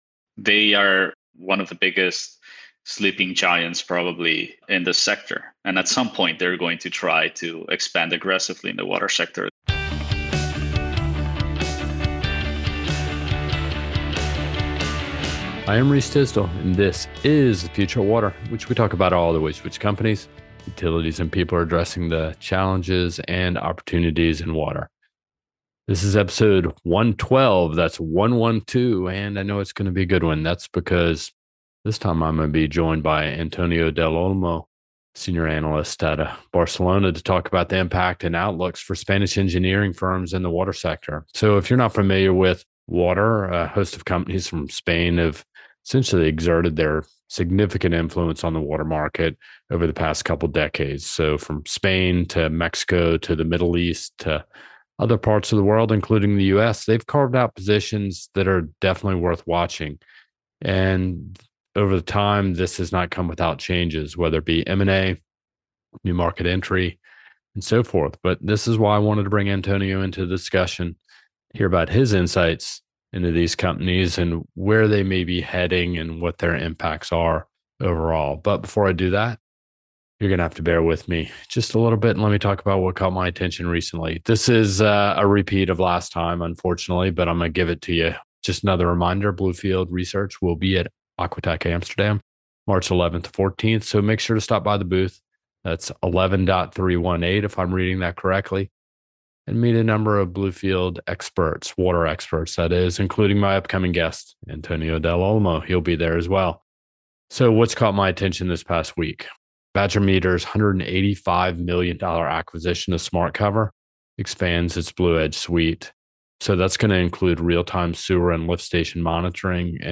Our water experts break down the implications of GS Inima’s pending sale, how Spanish firms are navigating market consolidation, and whether they can maintain leadership amid growing competition from French and Chinese players.